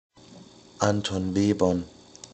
Anton Webern[a] (German: [ˈantoːn ˈveːbɐn]